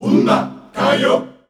Index of /90_sSampleCDs/Zero G Creative Essentials Vol 27 Voices Of Africa WAV/Voices of Africa Samples/Track 05